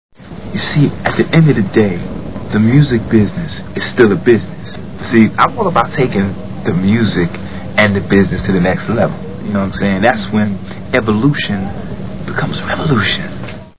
Snakes on a Plane Movie Sound Bites